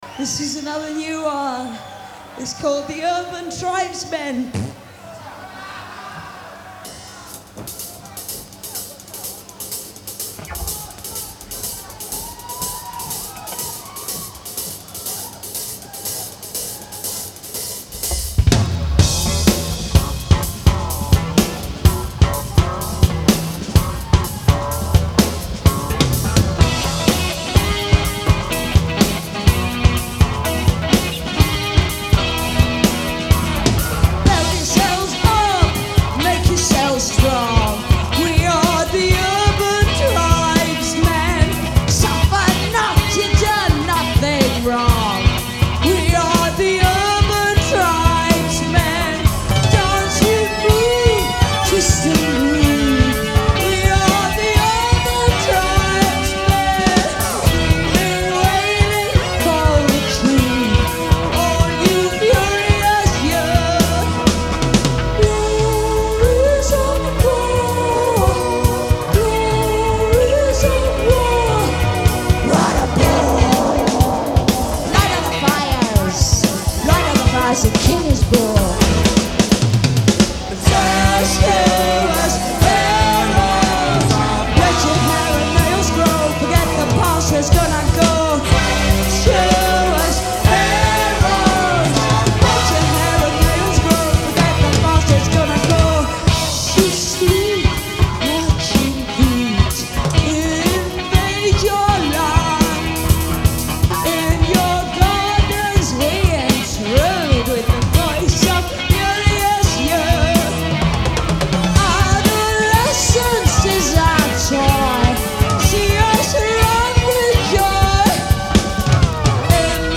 Genre : Rock
Live, Milan